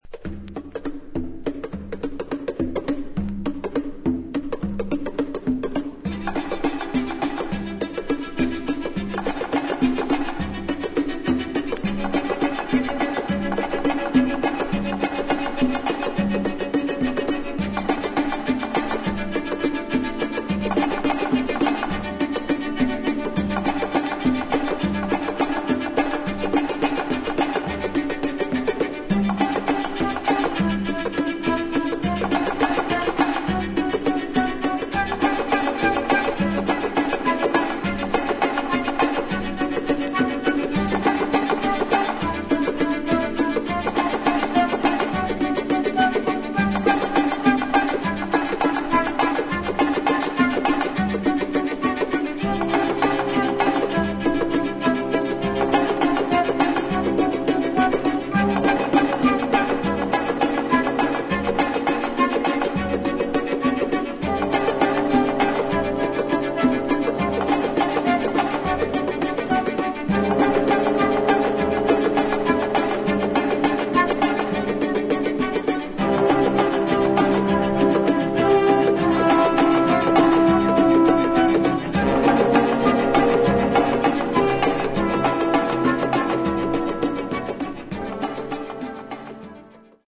ダイナミックなオーケストラと自作打楽器により素晴らしいミニマリズムなアンサンブルを披露